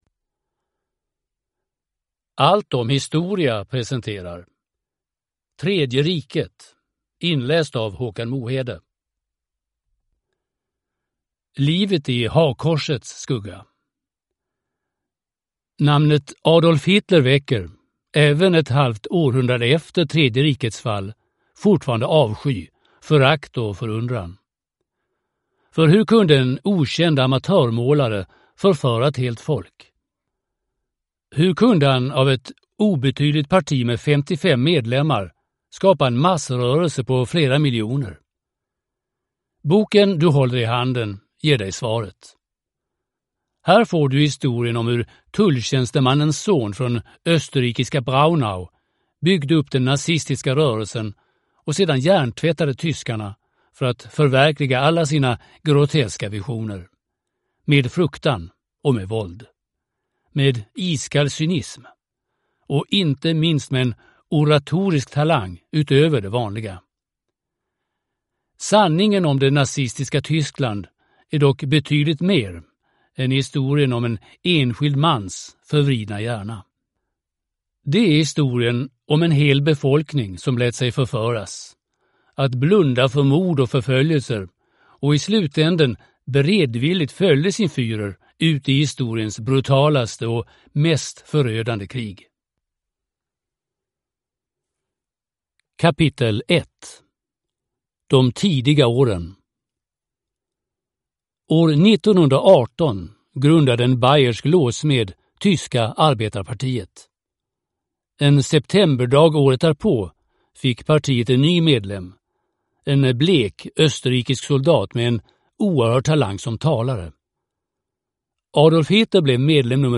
Tredje riket (ljudbok) av Allt om Historia